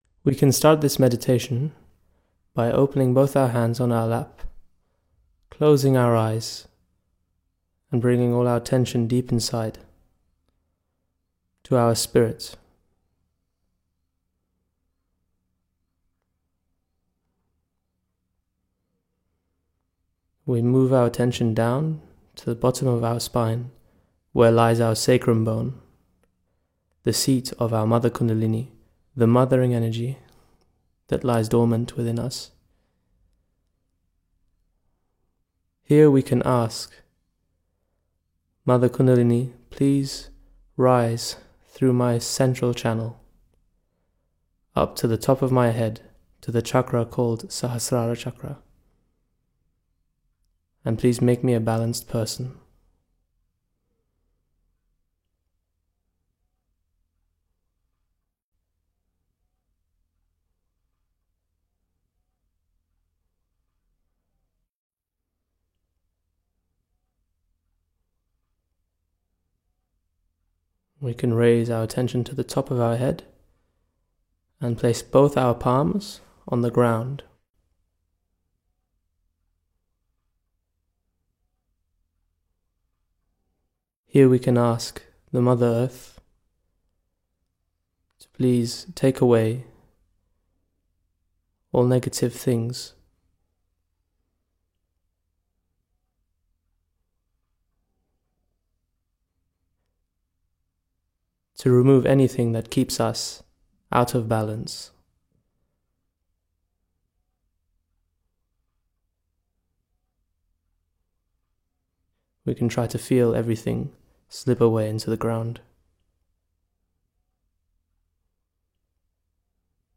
MEDITATION Shri Mataji Nirmala Devi founder 0:00 Delving Deeper All pervading power